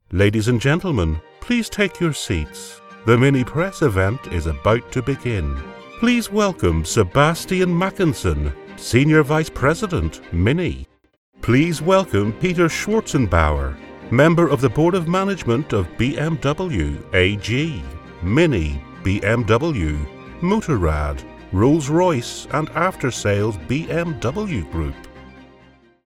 Announcements
I have a soft engaging Northern Irish Accent
Baritone , Masculine , Versatile . Commercial to Corporate , Conversational to Announcer . I have a deep, versatile, powerful voice, My voice can be thoughtful , authoritative , storytelling and funny . Confident and able to deliver with energy , humour and conviction .
RODE NT1A . TECPORTPRO , ISOLATED SOUND TREATED BOOTH. EDIT WITH AUDACITY